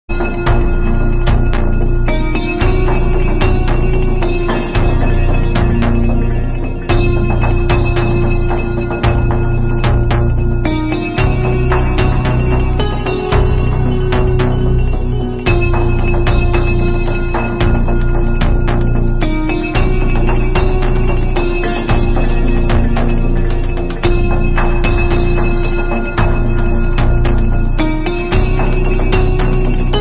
[Lo-Fi preview] Remixers Website